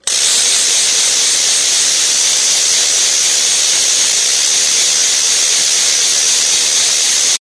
棘轮" 棘轮连续快速01
描述：专业管弦乐木制棘轮打击乐器的声音。连续且非常快。
Tag: 弹出 点击 按扣 棘轮 管弦乐 打击乐